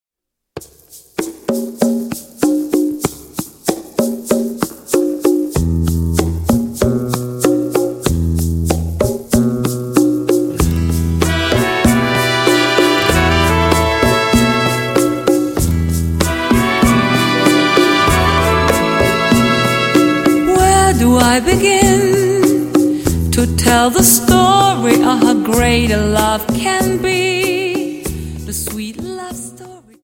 Dance: Rumba 24 Song